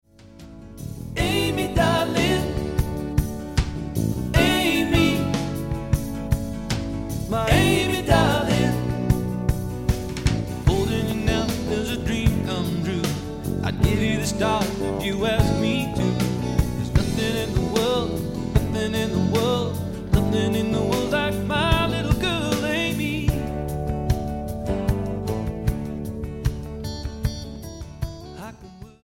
STYLE: Pop
with elements of soul